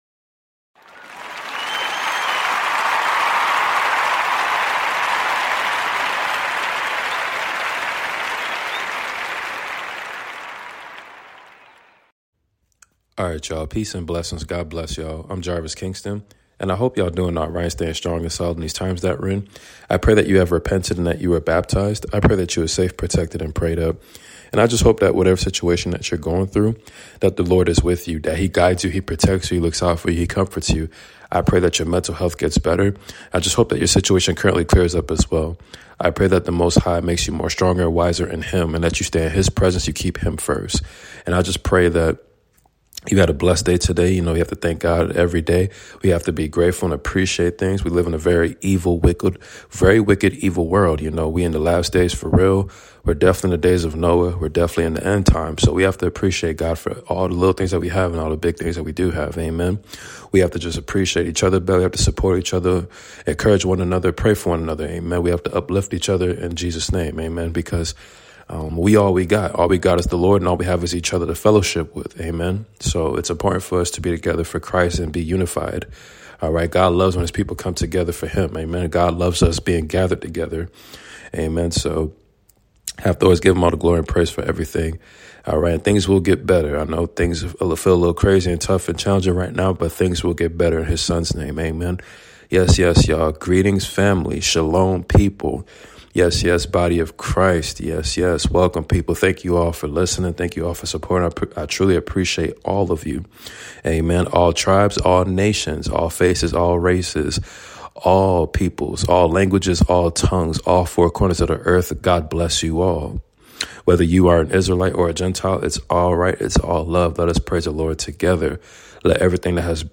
Bible study series ! 1 Kings 15-17 reading ! Elijah intro ! Pray